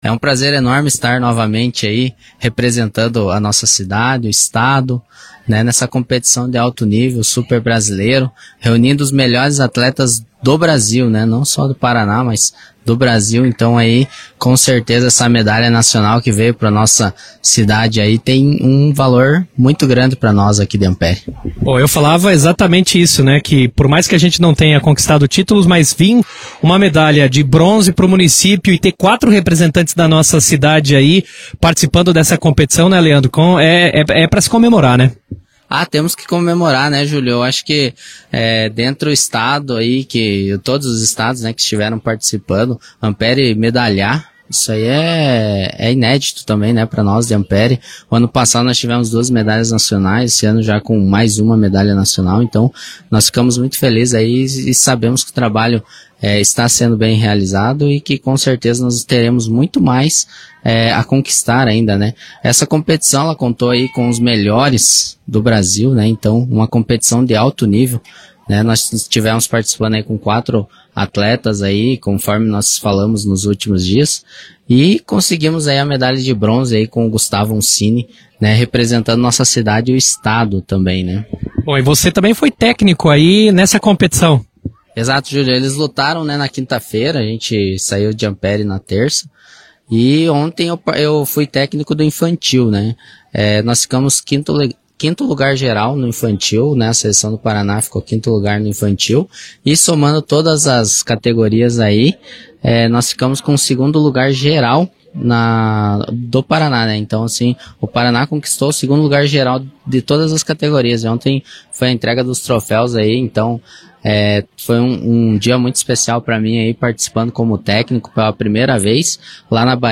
em entrevista à Rádio Ampére AM